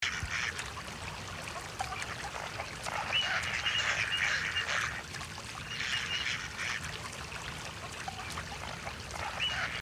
Animal Sound Effects Library
These sound clips are in the public domain (having been recorded by the U.S. Fish and Wildlife Service)
ducks_in_water.mp3